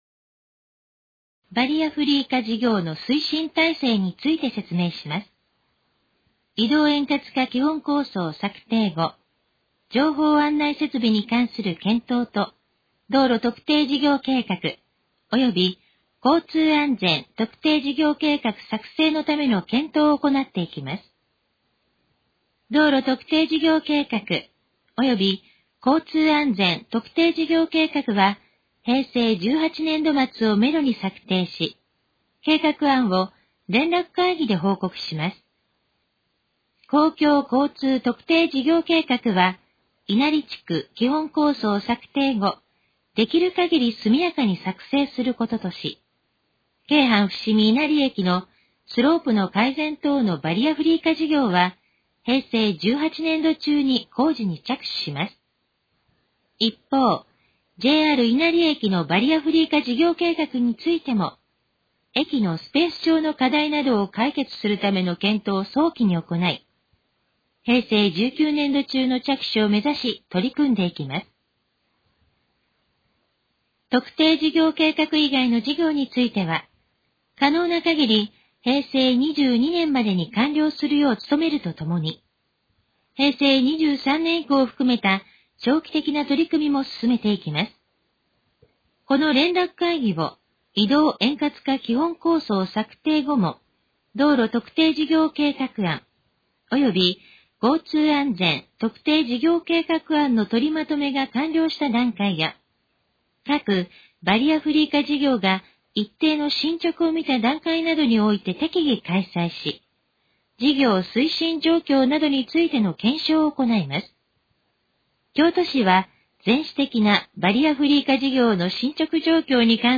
このページの要約を音声で読み上げます。
ナレーション再生 約255KB